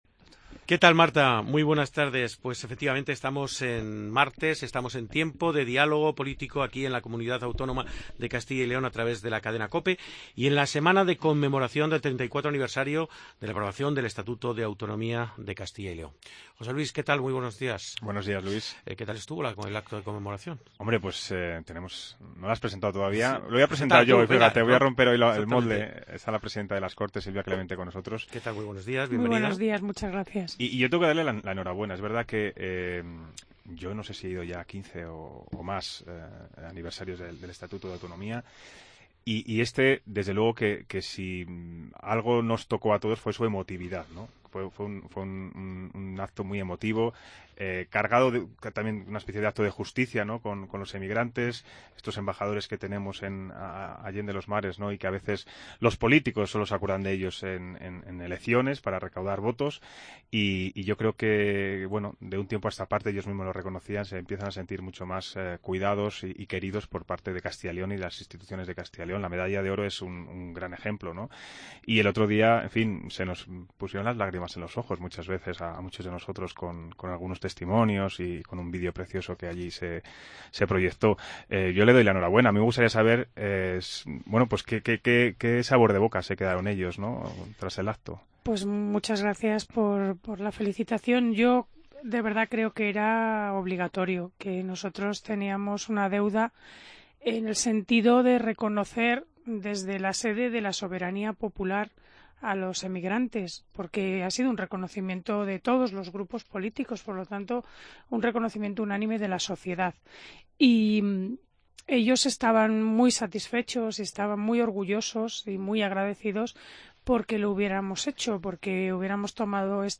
Tertulia Política 28-2-17